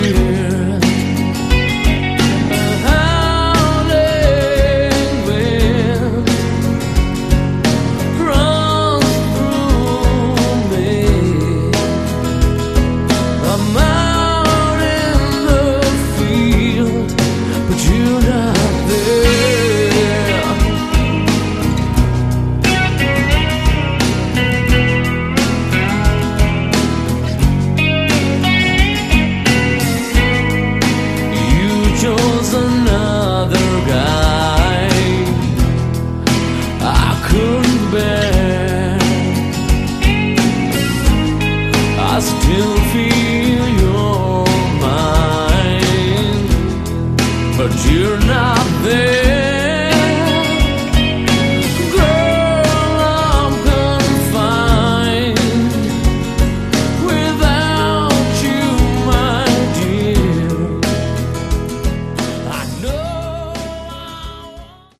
Category: Hard Rock
Vocals
Guitars
Bass
Drums
Keyboards
Backing Vocals